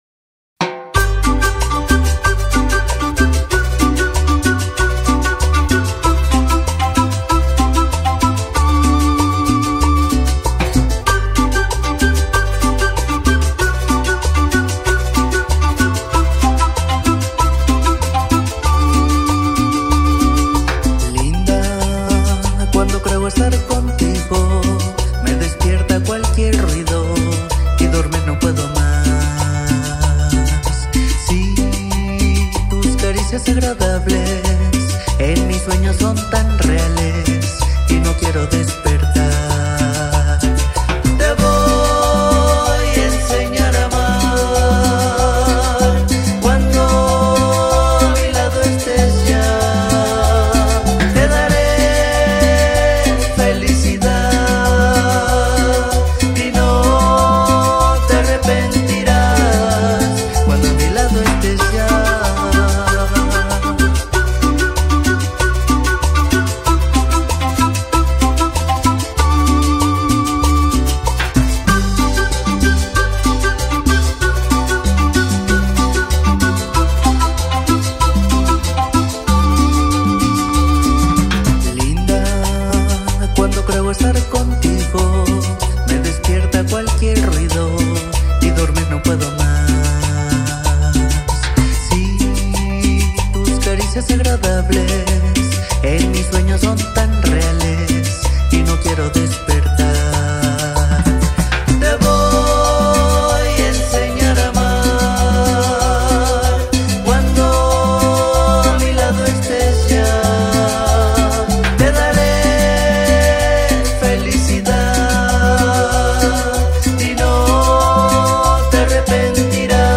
cumbia andina
musica tropical andina